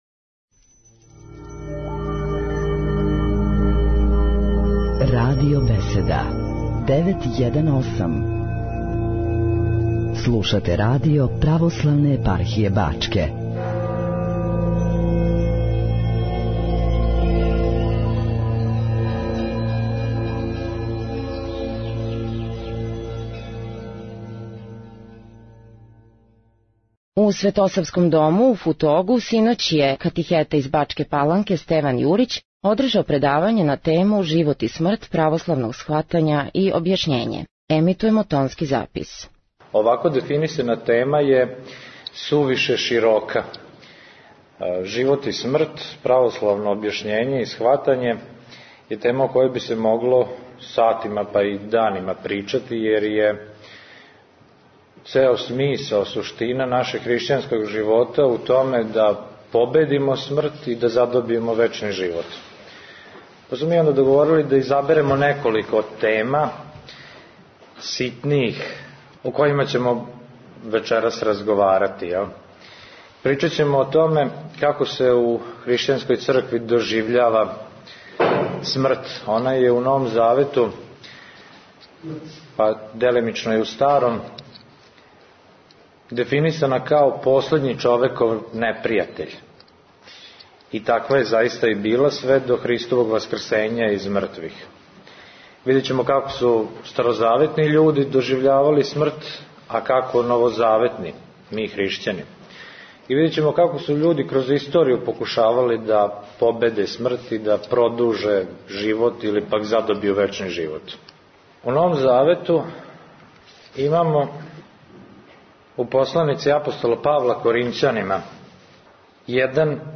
Ово је прво предавање у овогодишњем пролећном семестру.